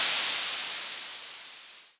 • Crash Single Shot G Key 02.wav
Royality free crash cymbal one shot tuned to the G note. Loudest frequency: 10078Hz
crash-single-shot-g-key-02-0fx.wav